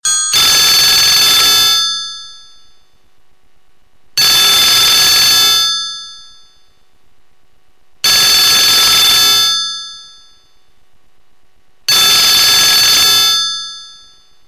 Рингтон Громкий звонок старого домашнего телефона